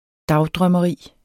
Udtale [ -dʁɶmʌˌʁiˀ ]